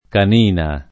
canina [ kan & na ]